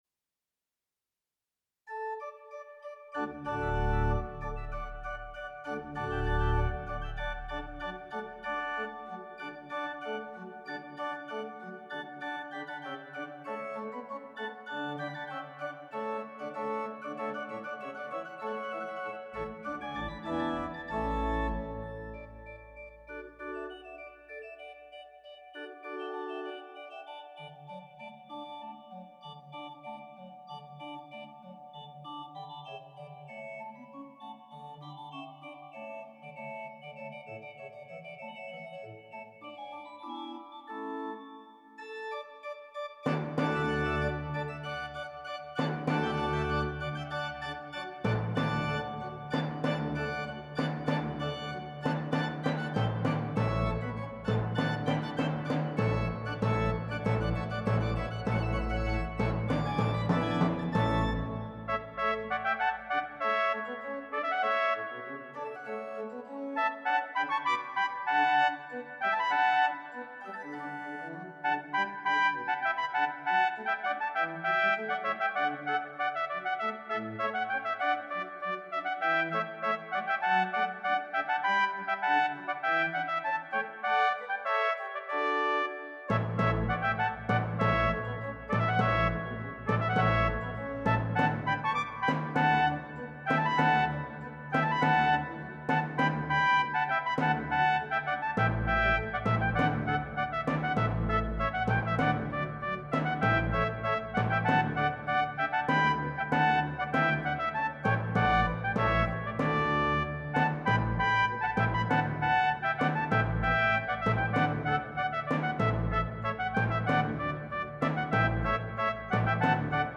La Rejouissance is the best-known movement from the Fireworks Music Suite. This arrangement features pipe organ stops, orchestral brass, and timpani.